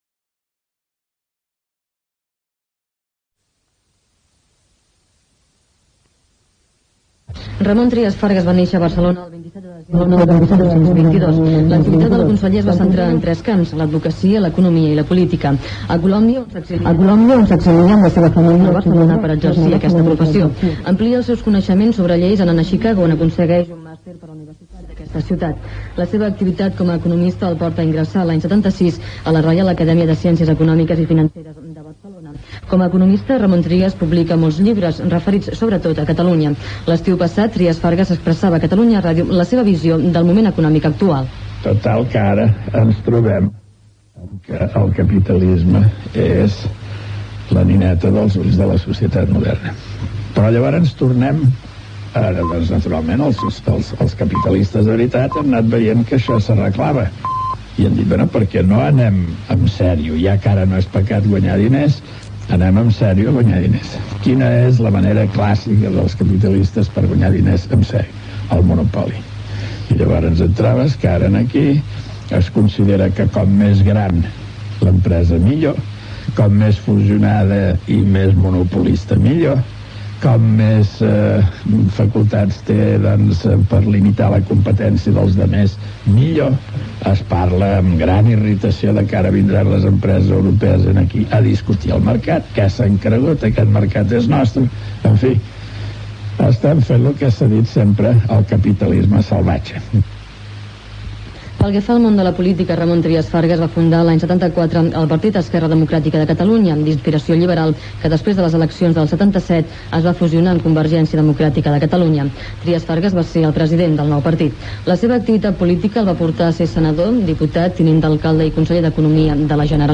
Perfil biogràfic de l'economista i polític Ramon Trias Fargas en el dia de la seva mort, amb fragments d'algunes de les seves declaracions, informació des de l'hospital Germans Trias de Badalona, reconstrucció de com ha estat la seva mort en un míting celebrat a Ocata (El Masnou).
Informatiu